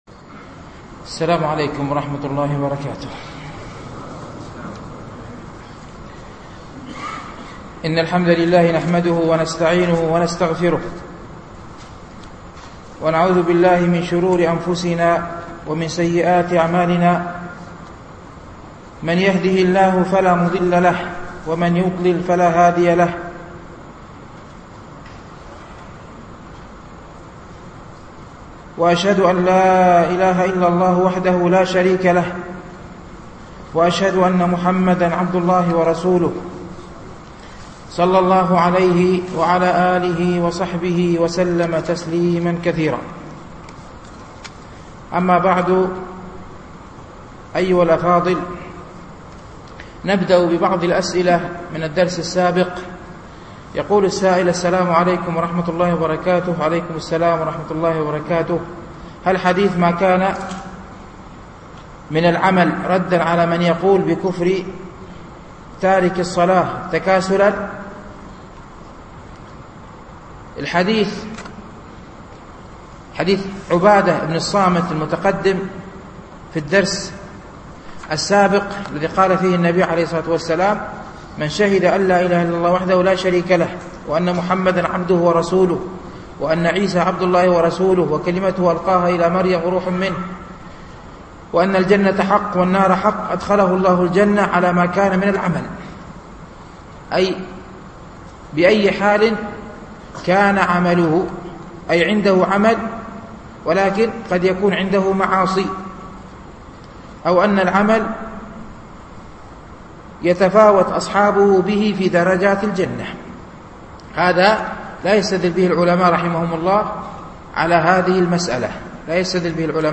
شرح رياض الصالحين - الدرس الثاني والعشرون بعد المئة
التنسيق: MP3 Mono 22kHz 32Kbps (VBR)